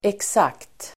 Uttal: [eks'ak:t]